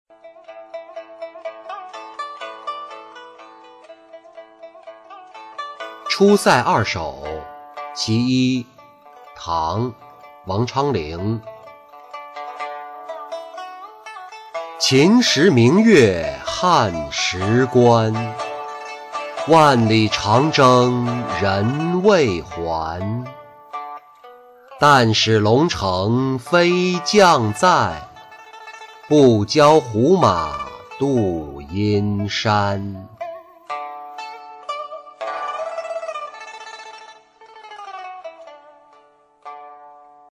出塞二首·其一-音频朗读